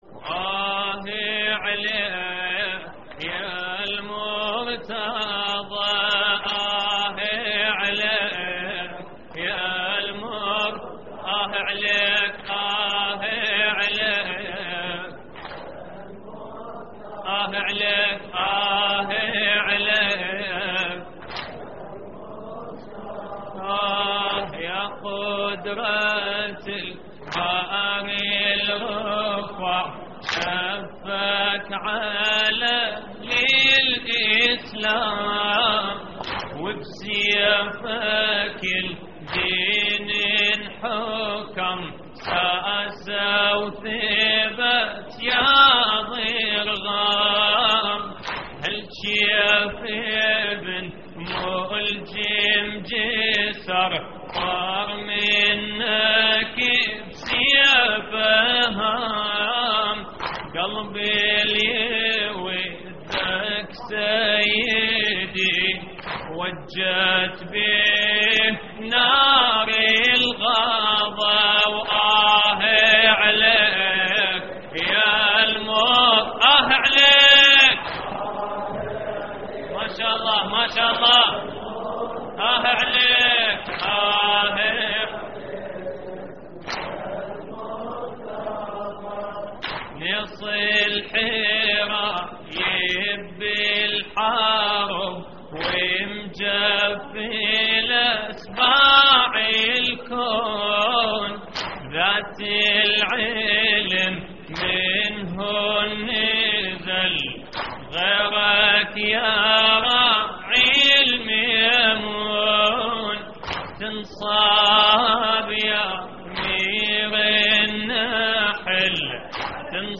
تحميل : آه عليك يالمرتضى / مجموعة من الرواديد / اللطميات الحسينية / موقع يا حسين